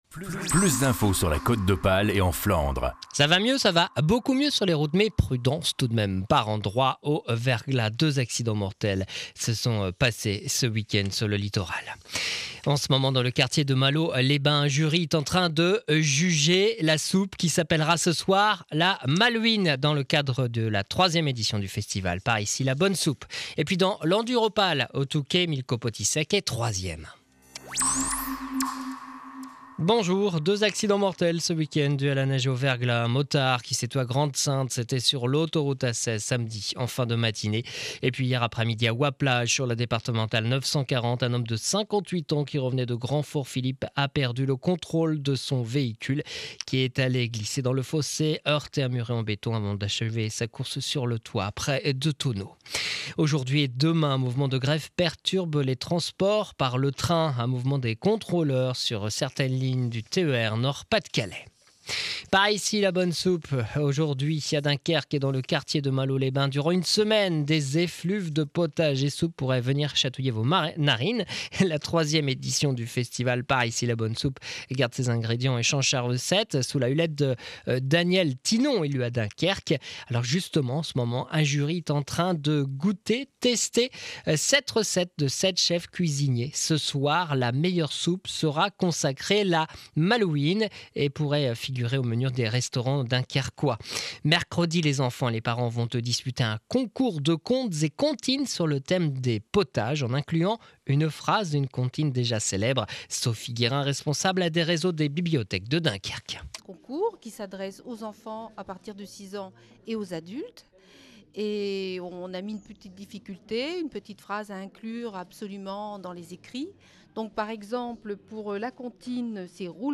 Edition de 12h journal de Dunkerque